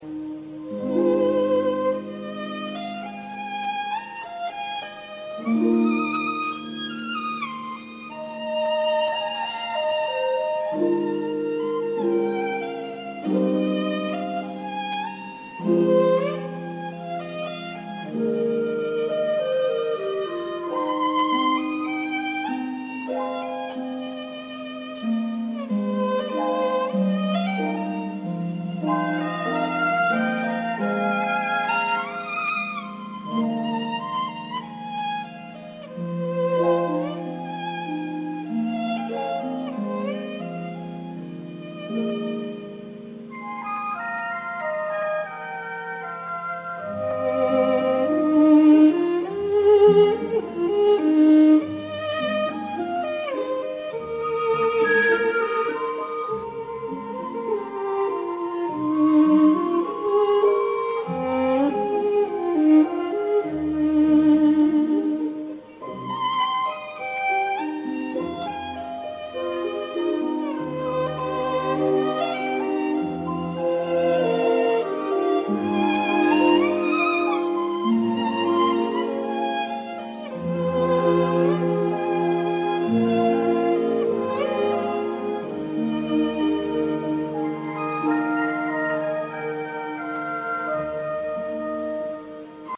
Music -- Traditional Chinese Music
Liang Zhu Sampler: a very beautiful melody played by an "erhu - want-to-be" violin.